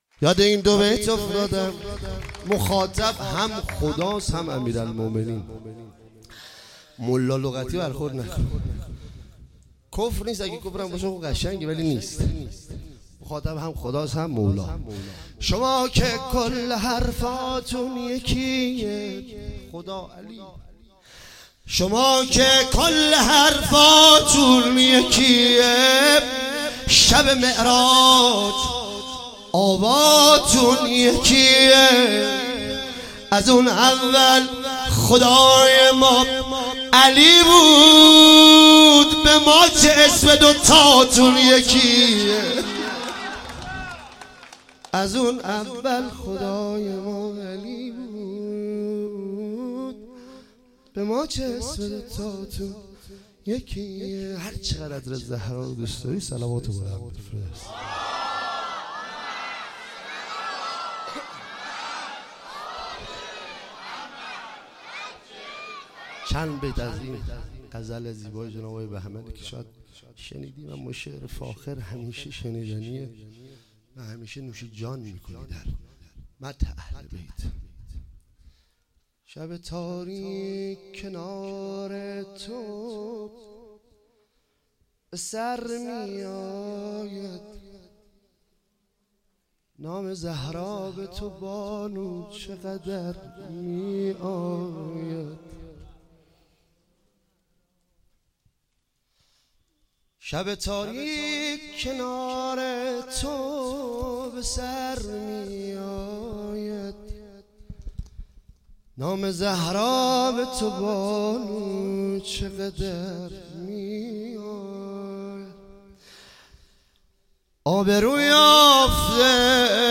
سرود